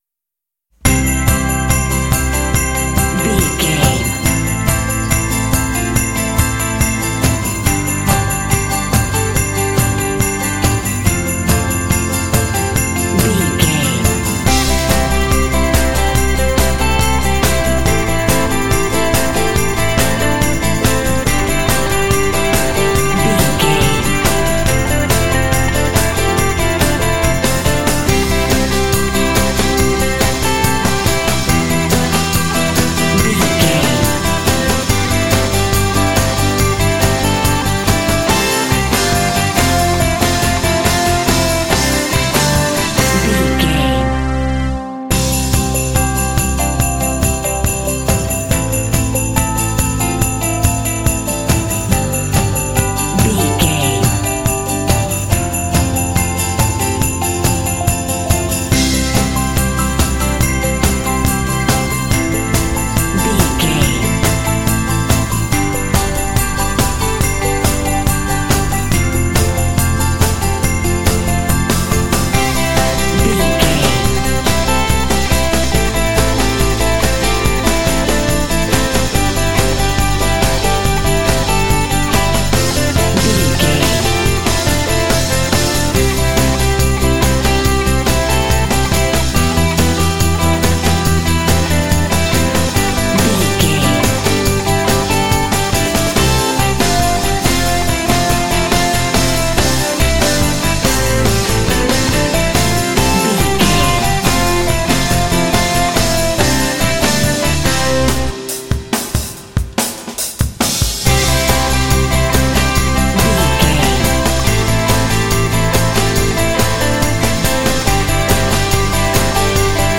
Ionian/Major
happy
festive
joyful
drums
bass guitar
electric guitar
contemporary underscore